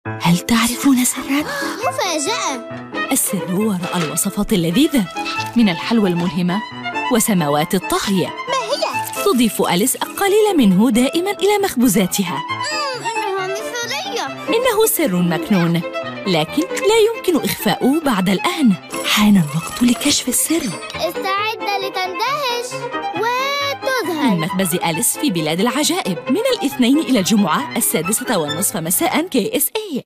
Commerciale, Profonde, Jeune, Amicale, Corporative
Corporate